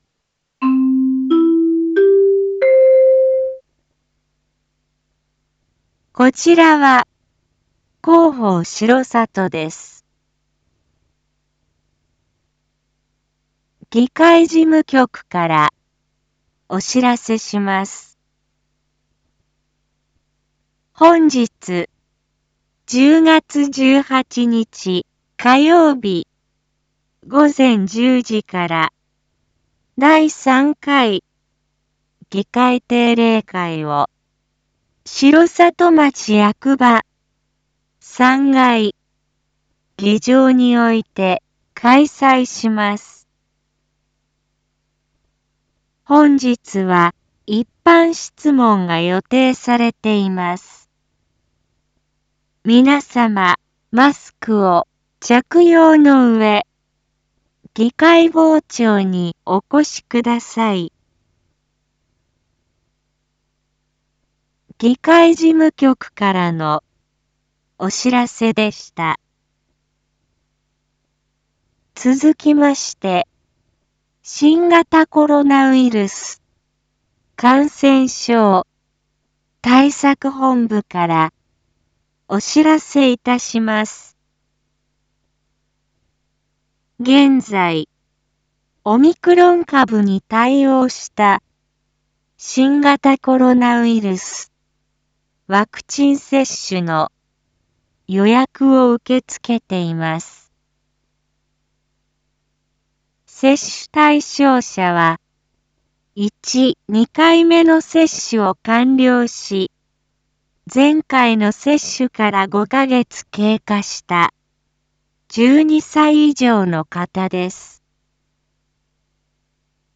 一般放送情報
Back Home 一般放送情報 音声放送 再生 一般放送情報 登録日時：2022-10-18 07:03:15 タイトル：議会開催案内・ワクチン接種について インフォメーション：こちらは広報しろさとです。